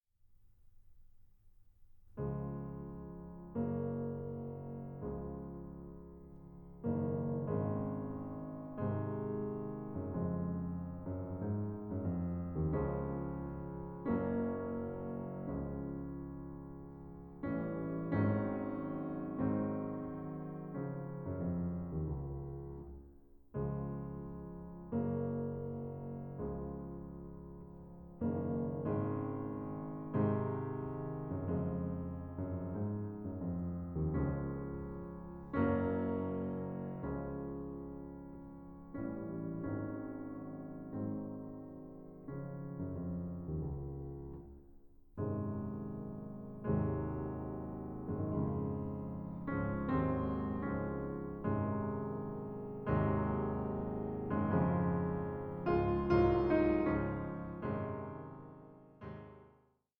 Piano Sonata No. 23 in F minor, Op. 57